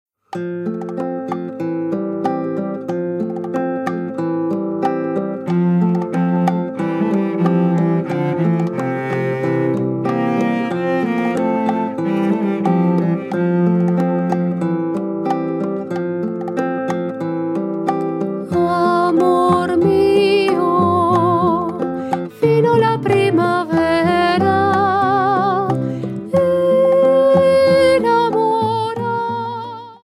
GRABADO EN planet estudio, Blizz producciónes
SOPRANO
VIOLONCELLO SOLO Y ENSAMBLES